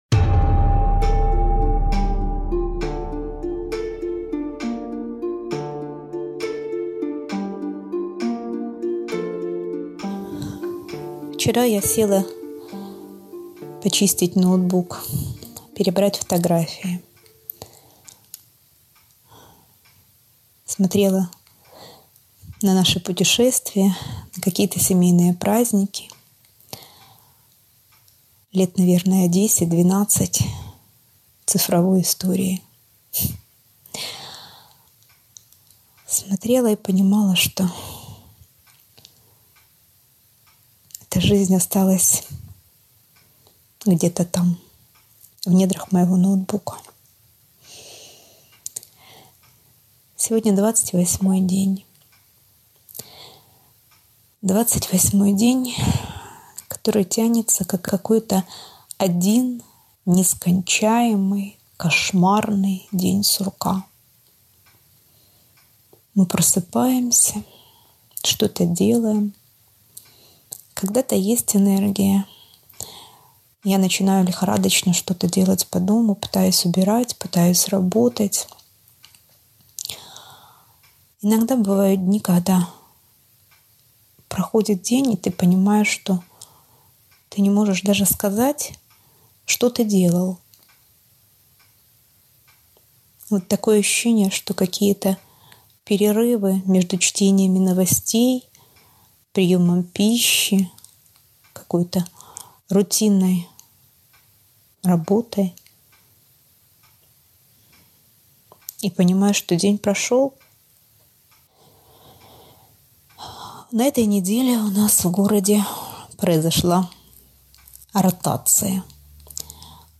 Месяц войны – в монологе из Херсона